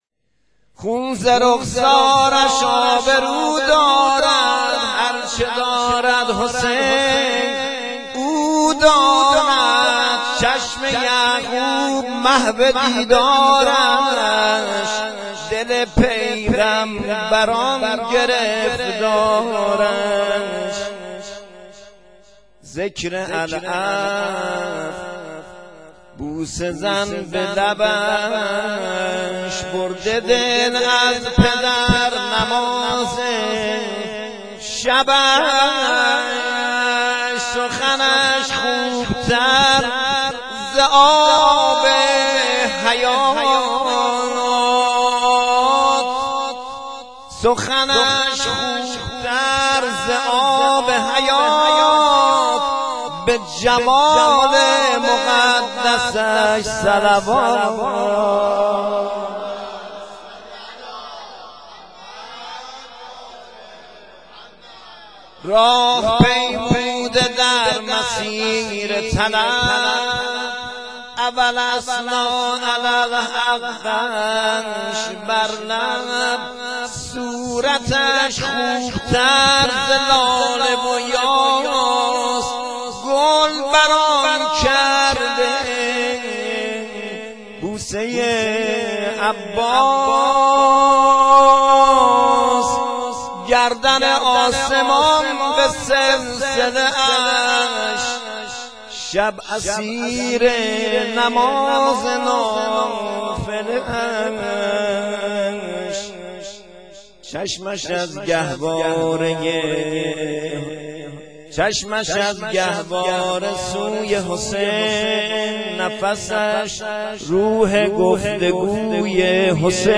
مدیحه سرایی و سرود
شام میلاد حضرت علی اکبر 1392